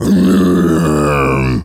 gorilla_growl_deep_02.wav